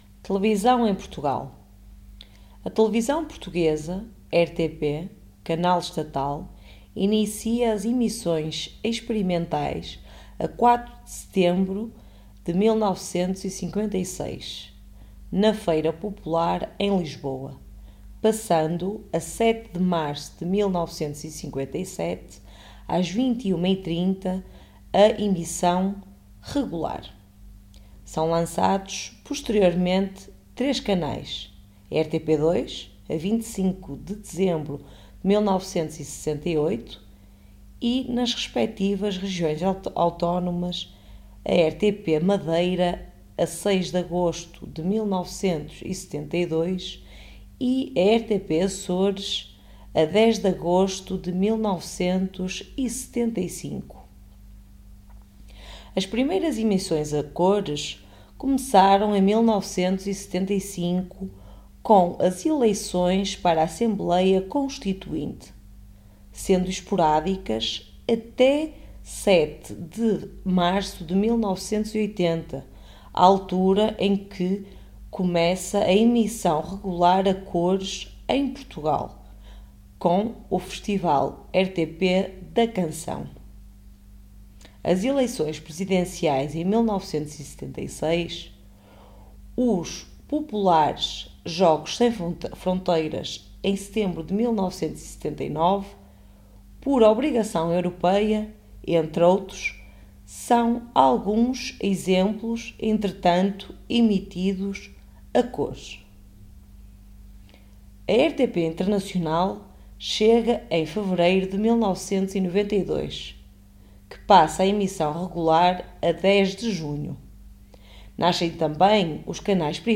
Our article text and audio are specifically aimed towards the Portuguese language from Portugal, and not from Brazil or other Portuguese speaking countries.